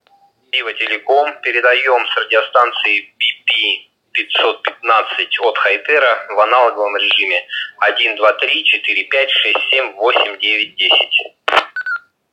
Пример модуляции (передачи) радиостанций BP-515 в аналоговом режиме:
bp-515-tx-analog.wav